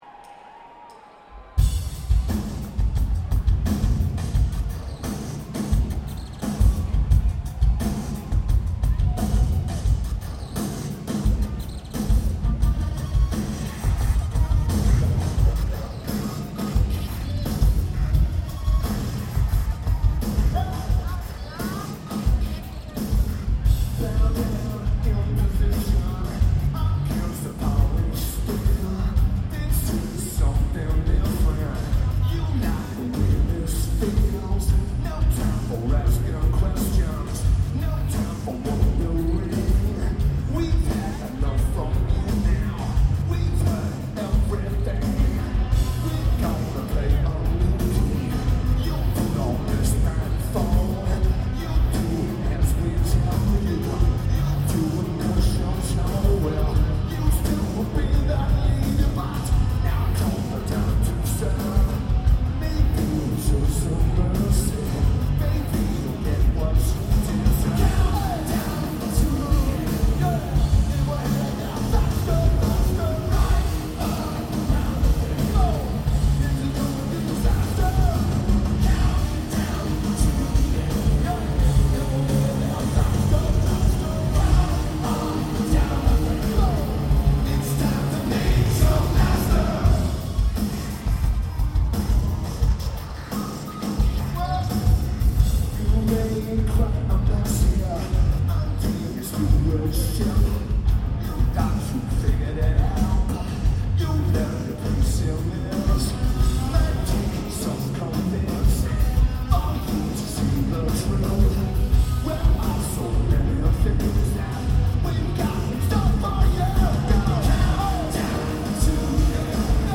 Verizon Wireless Music Center
Lineage: Audio - AUD (DPA 4060 (HEB) + BB + Edirol R09-HR)